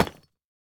Minecraft Version Minecraft Version 1.21.5 Latest Release | Latest Snapshot 1.21.5 / assets / minecraft / sounds / block / deepslate_bricks / place2.ogg Compare With Compare With Latest Release | Latest Snapshot